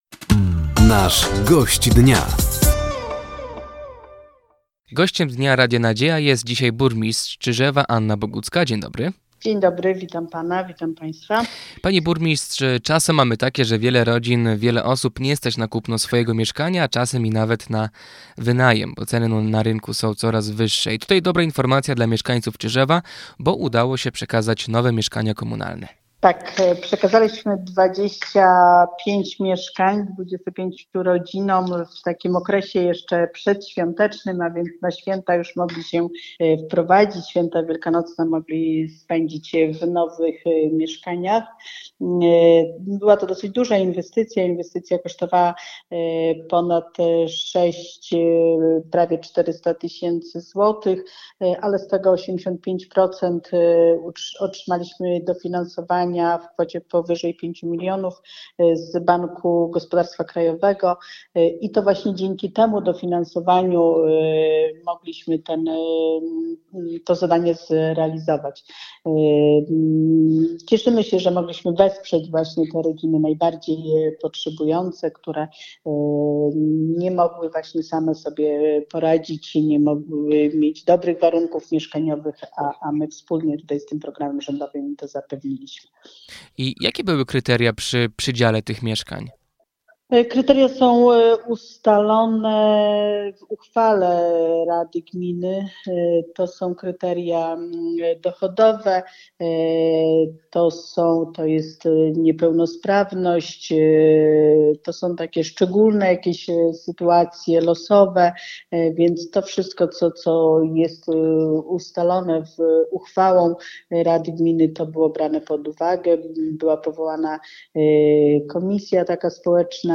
Gościem Dnia Radia Nadzieja była burmistrz Czyżewa, Anna Bogucka. Tematem rozmowy były między innymi nowe mieszkania komunalne oraz budowa przedszkola miejsko-gminnego z funkcją żłobka.